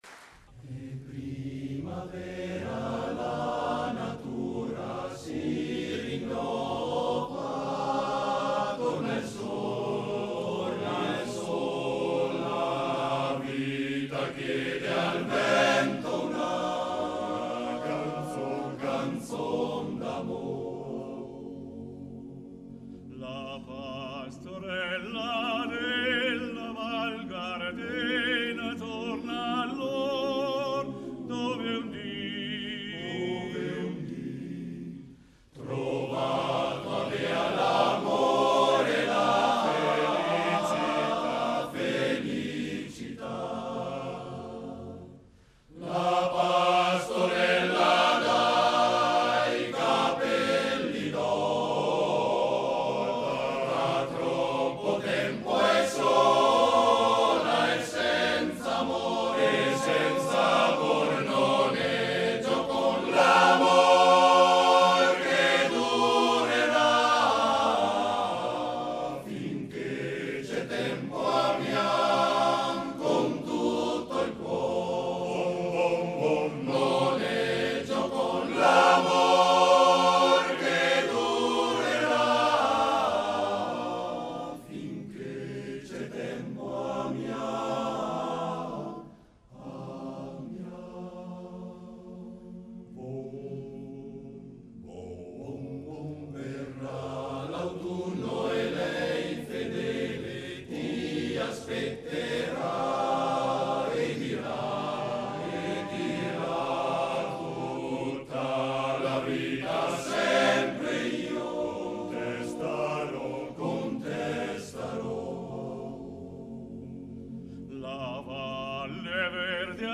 Canto delle Alpi Svizzere